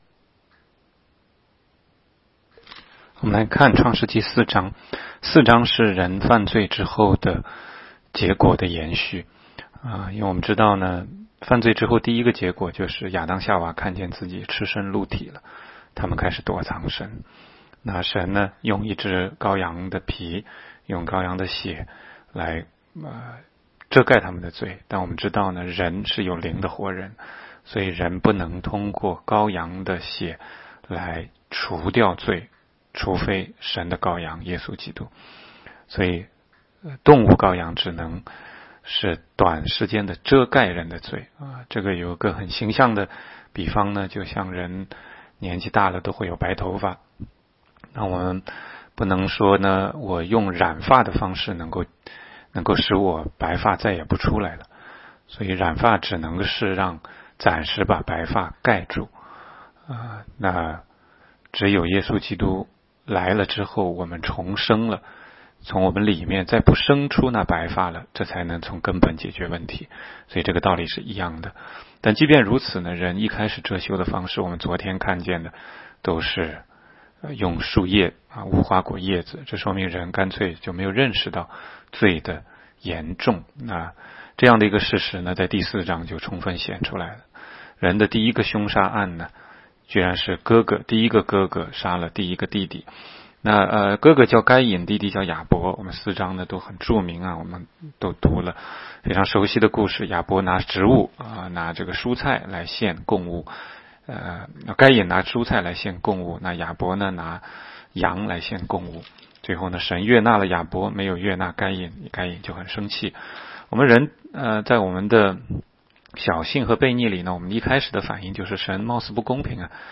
16街讲道录音 - 每日读经-《创世记》4章